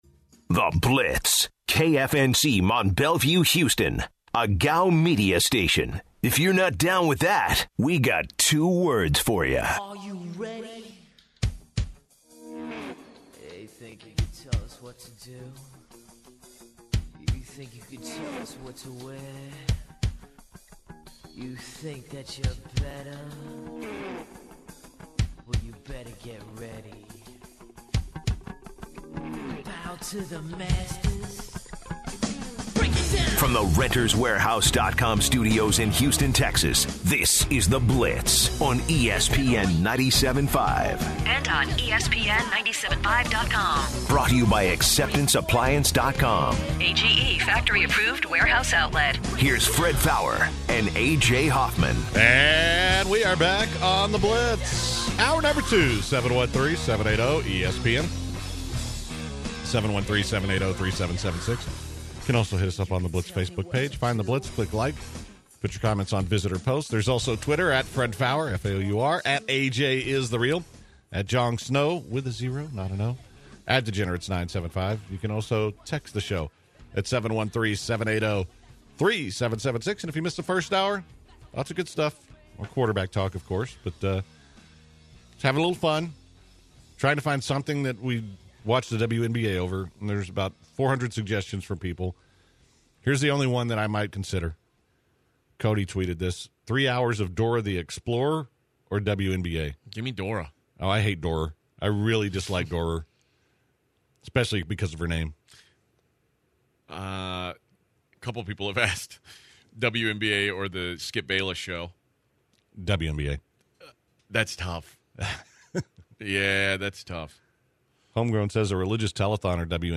On the second hour they take more what are the odds calls, they talk about a racist man on an airplane, Lou Willams to the rockets and the Zadok Jewelers Gem of the day.